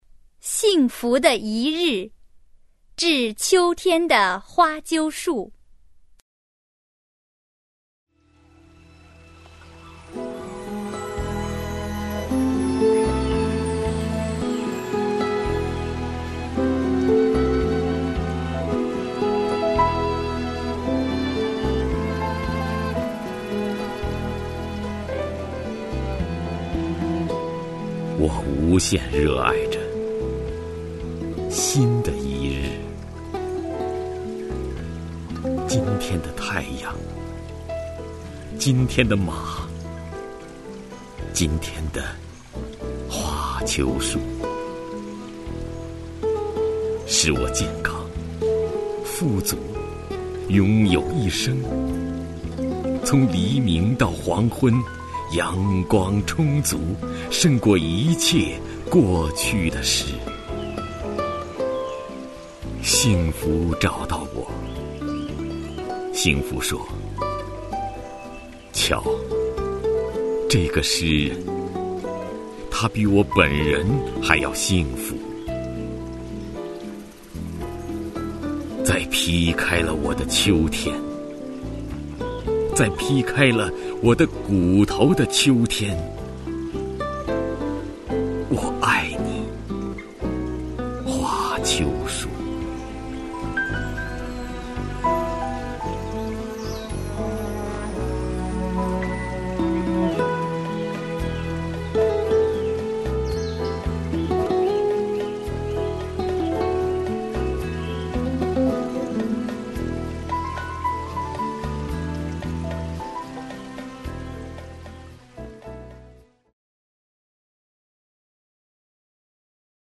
徐涛朗诵：《幸福的一日——致秋天的花楸树》(海子)
XingFuDeYiRi-ZhiQiuTianDeHuaQiuShu_HaiZi(XuTao).mp3